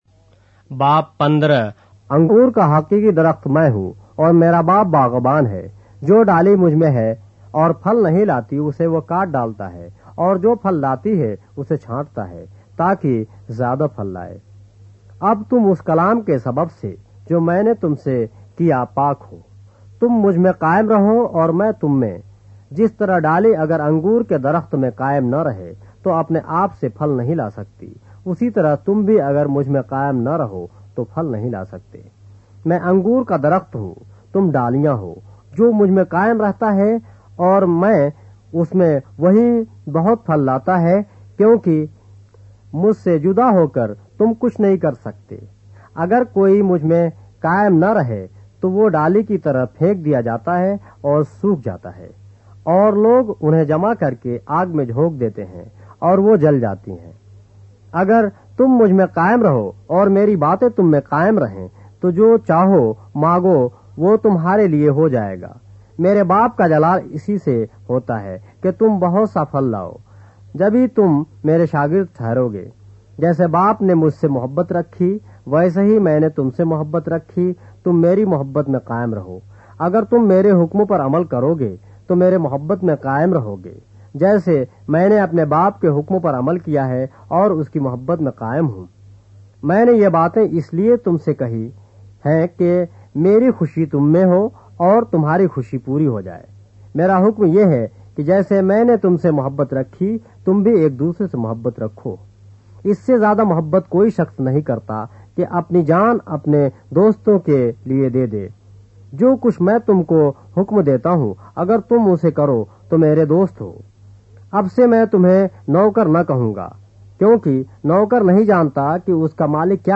اردو بائبل کے باب - آڈیو روایت کے ساتھ - John, chapter 15 of the Holy Bible in Urdu